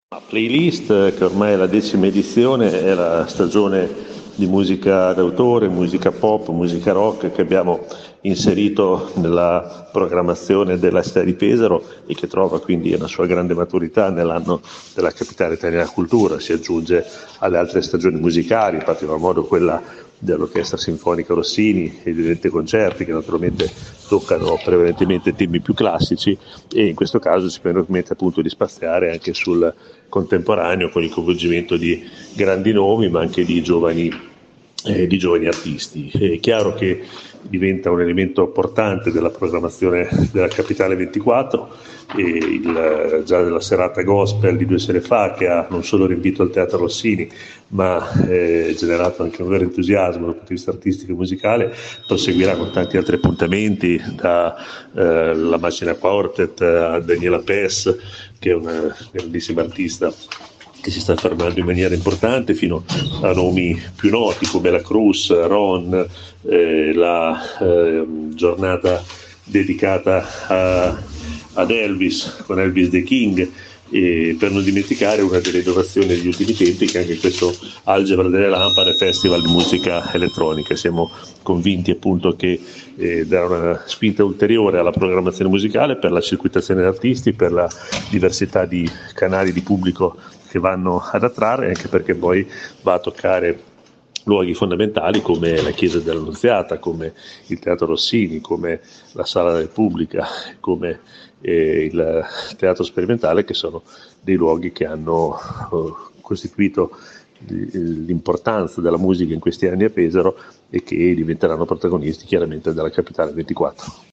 Pesaro, Capitale italiana della cultura 2024 e Città Creativa UNESCO per la Musica, rinnova, accanto alla già ampia offerta musicale, l’appuntamento con Playlist Pesaro, rassegna che torna per la decima edizione da gennaio a maggio 2024 al Teatro Rossini, allo Sperimentale e alla Chiesa dell’Annunziata su iniziativa del Comune di Pesaro e dell’AMAT, in collaborazione con Regione Marche e MiC. Ai nostri microfoni: Daniele Vimini, Vice Sindaco di Pesaro e Assessore alla Bellezza.